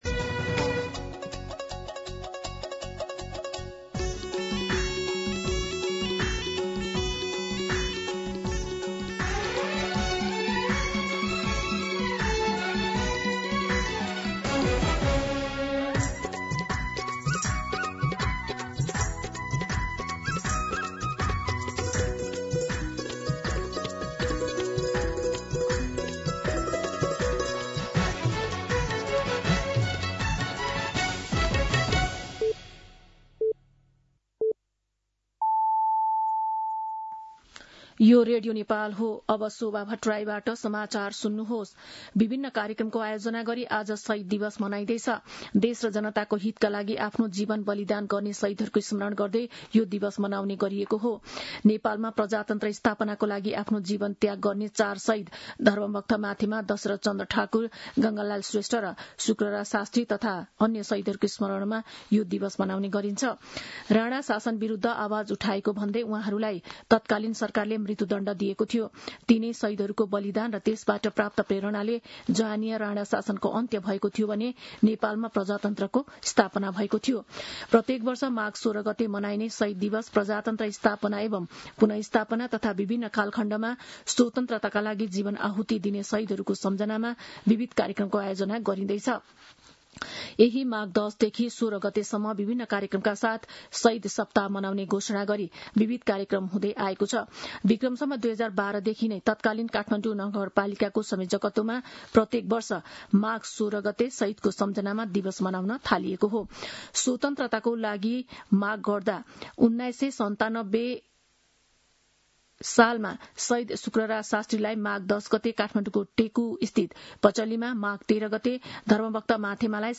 मध्यान्ह १२ बजेको नेपाली समाचार : १६ माघ , २०८२